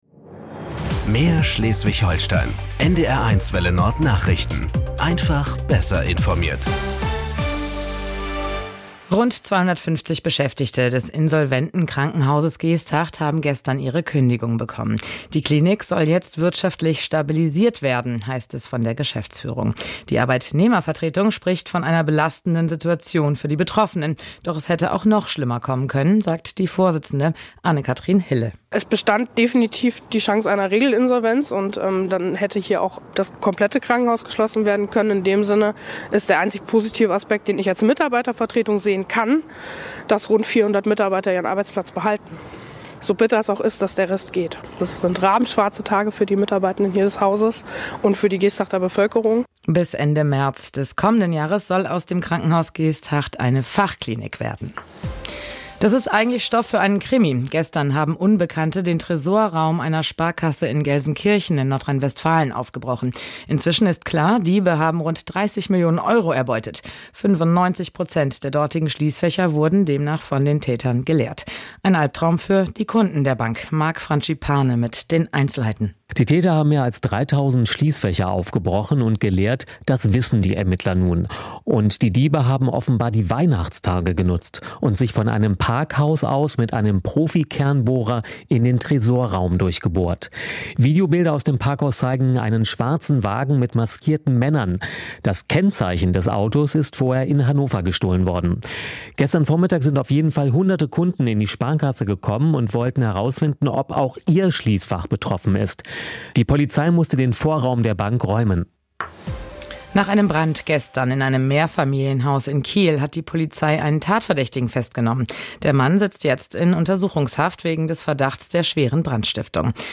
Nachrichten 18:00 Uhr - 30.12.2025